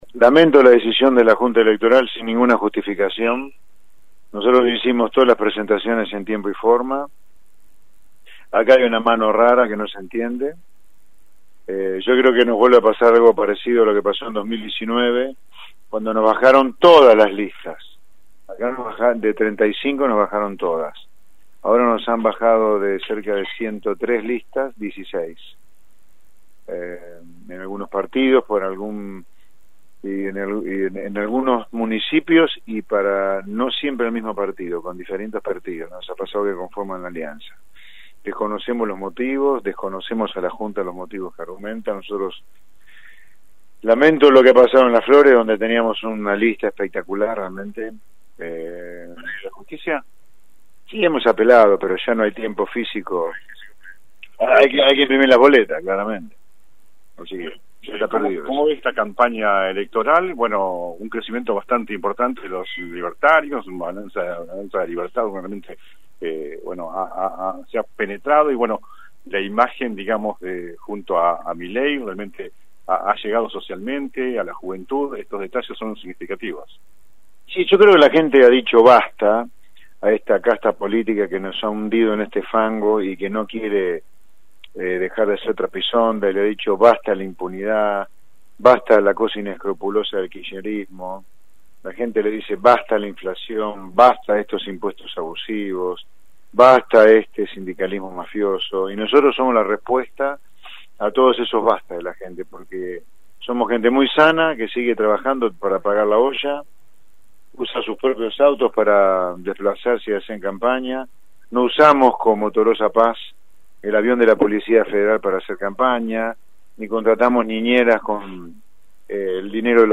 En diálogo exclusivo con la 91.5Mhz. y consultado sobre la no participación de Avanza Libertad en varios distritos bonaerenses, Espert manifestó que «lamento la decisión de la Junta Electoral sin ninguna justificación.